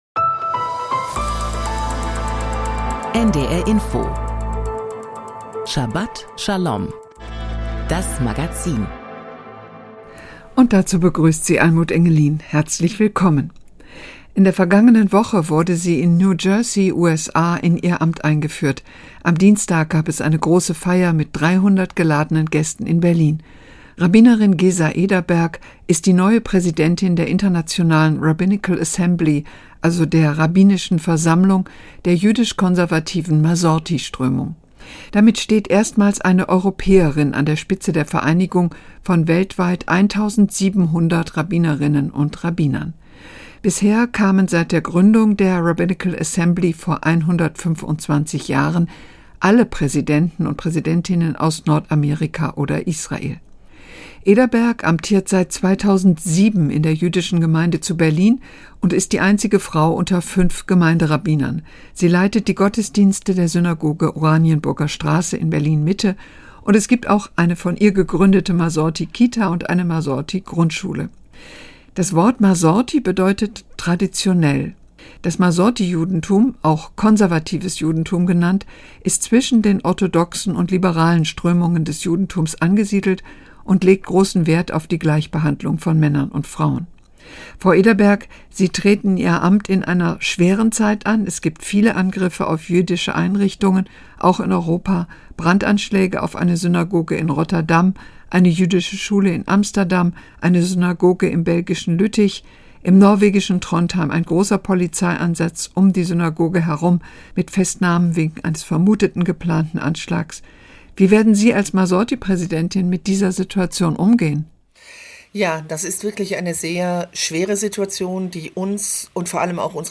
Vor großen Herausforderungen Interview